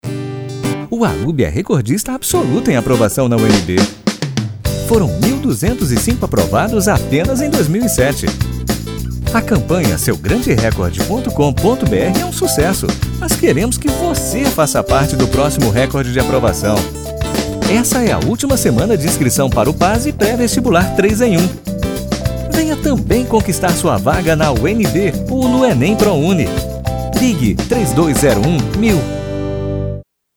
Sprechprobe: Sonstiges (Muttersprache):
Portuguese voice over artist.